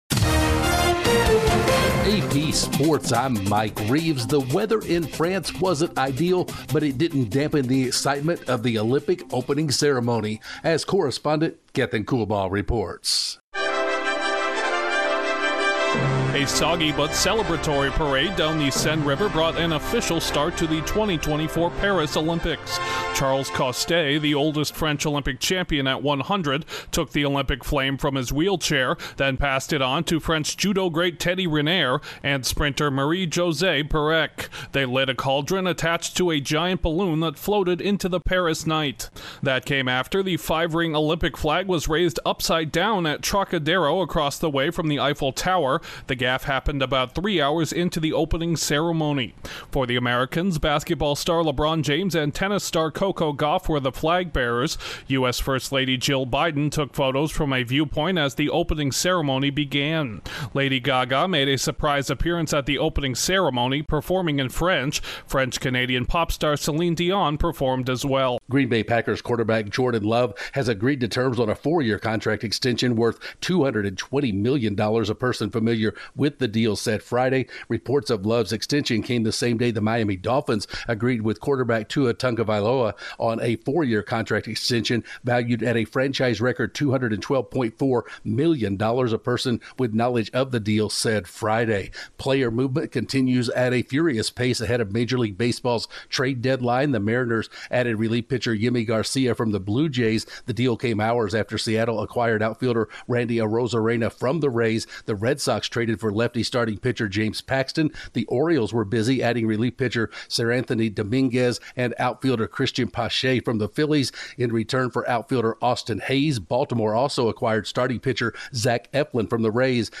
Sports News from the Associated Press / The latest in sports